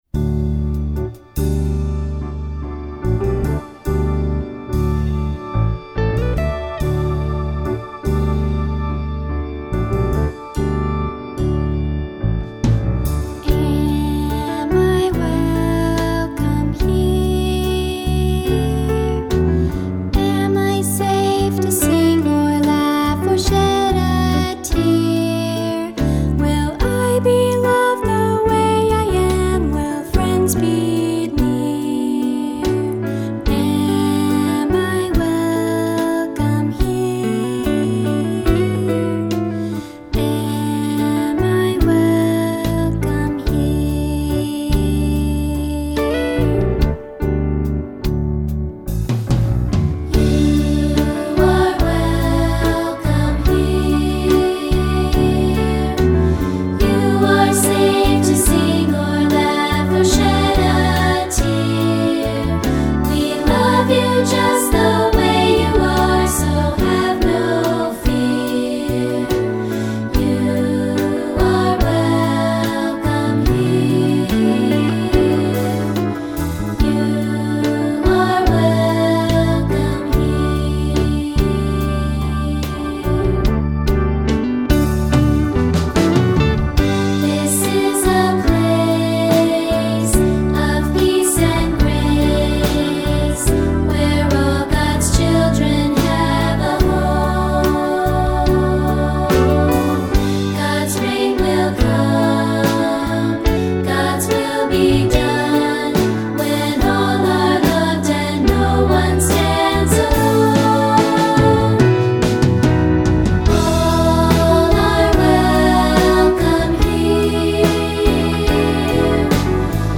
Voicing: 2-Part and Piano